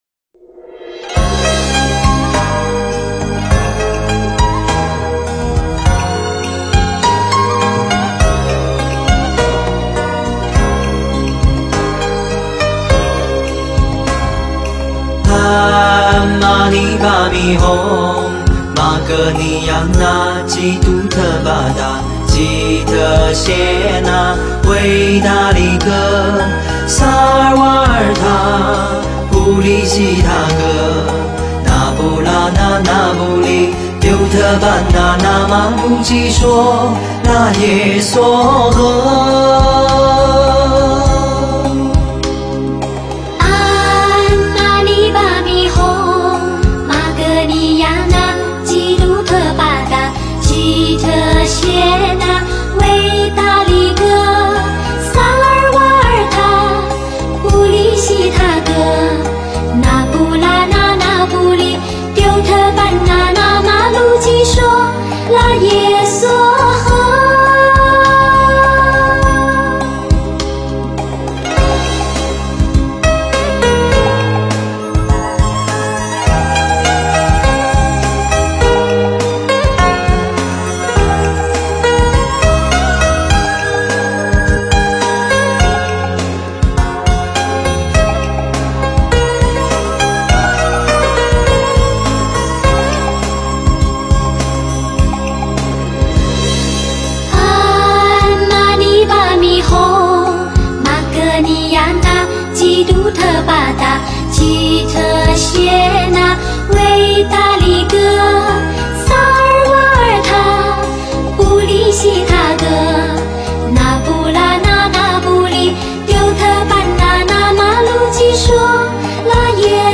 观音灵感真言 诵经 观音灵感真言--佛教音乐 点我： 标签: 佛音 诵经 佛教音乐 返回列表 上一篇： 地藏经 下一篇： 观音行 相关文章 四大祝延--普陀山梵唄 四大祝延--普陀山梵唄...